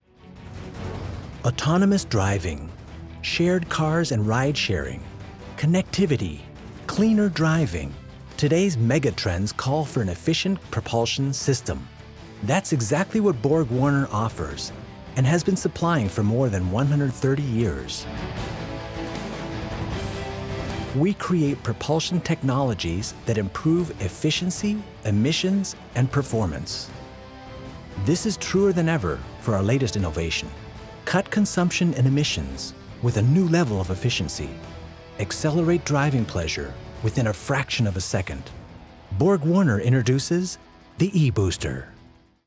US-Amerikaner, wohnhaft in Deutschland, English Native Speaker, Image-Filme, Erklär-Videos, spreche auch Deutsh
mid-atlantic
Sprechprobe: Werbung (Muttersprache):